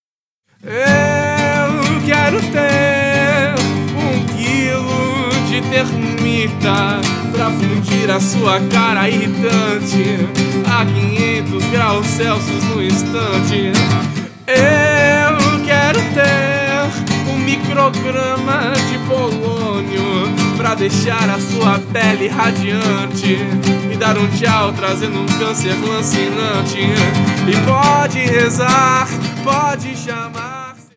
Backing Vocals e violão